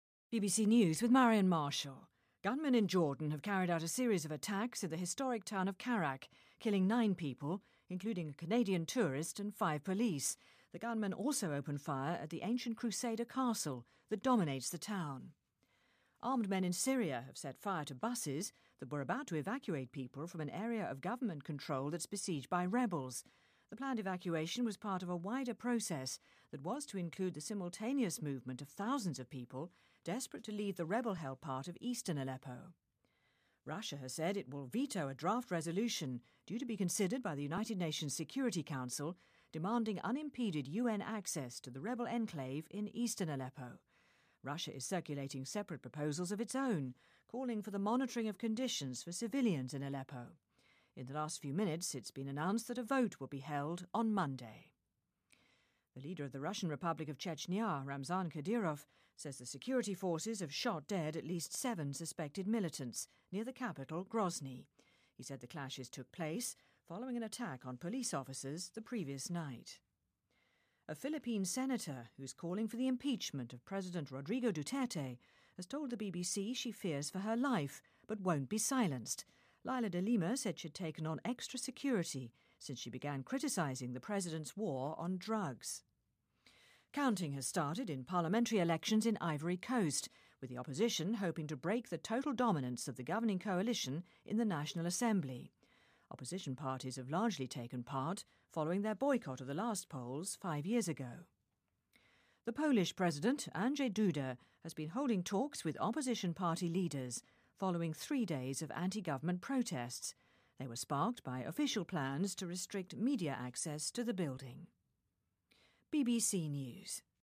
BBC news,科特迪瓦议会选举计票工作开始